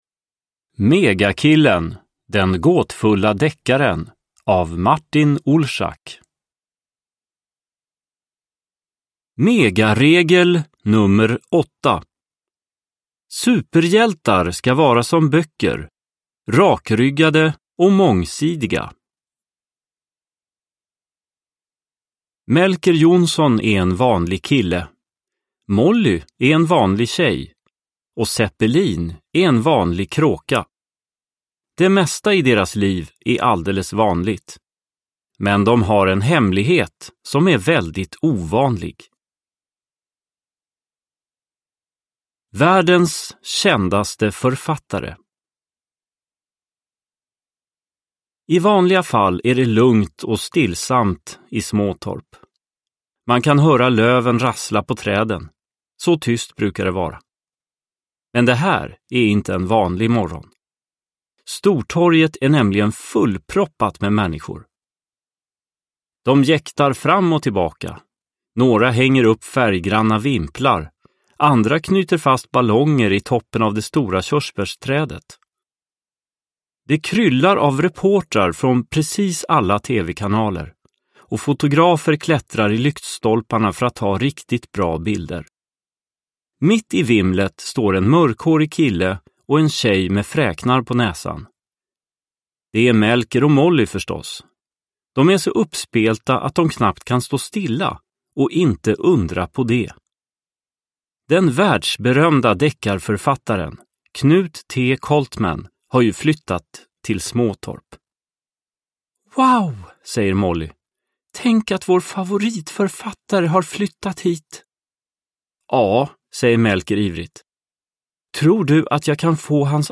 Megakillen. Den gåtfulla deckaren – Ljudbok – Laddas ner